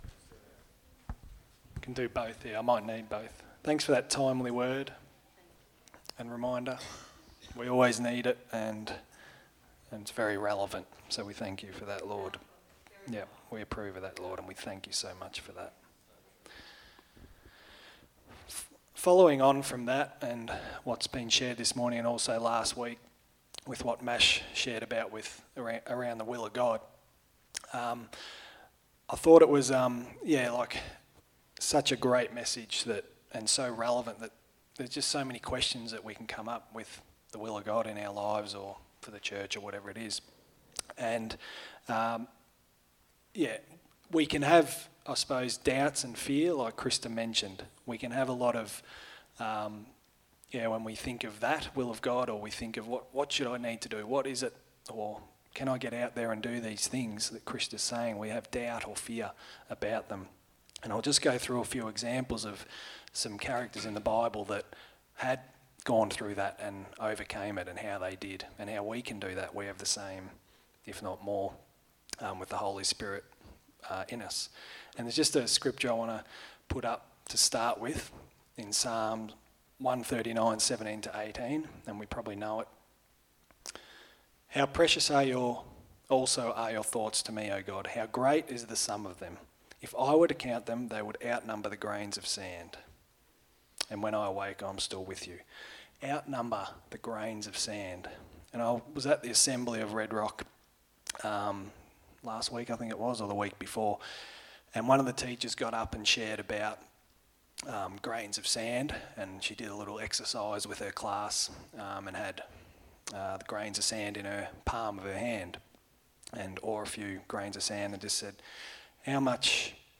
Sunday Message